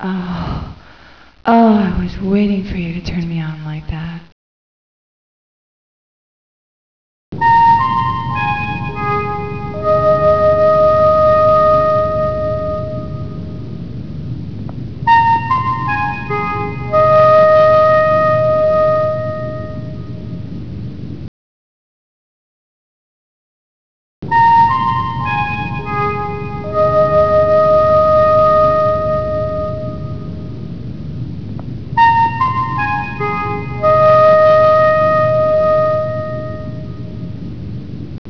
Wav voice public domain, tones from